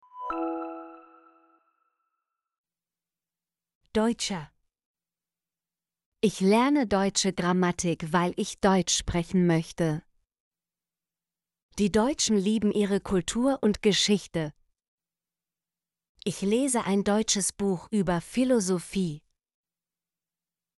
deutsche - Example Sentences & Pronunciation, German Frequency List